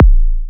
edm-kick-09.wav